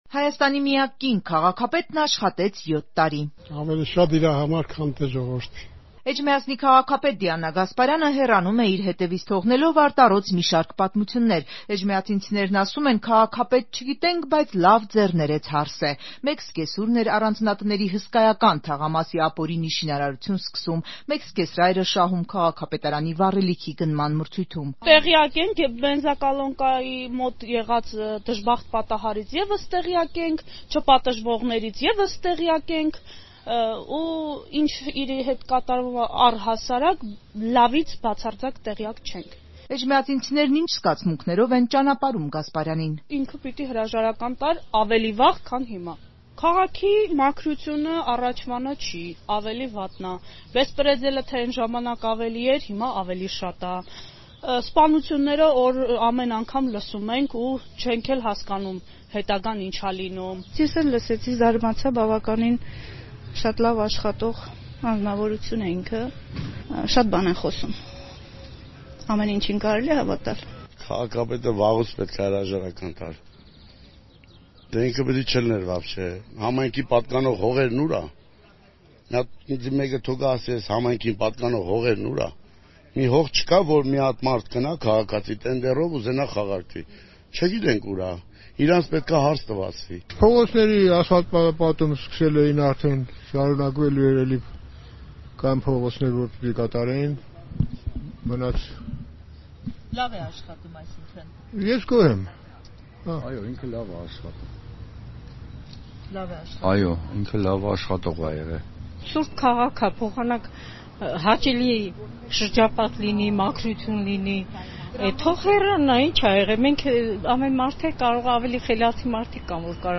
Ռեպորտաժներ
«Վաղուց պիտի հրաժարական տար», «լավ է աշխատել». էջմիածինցիները՝ հեռացող քաղաքապետի մասին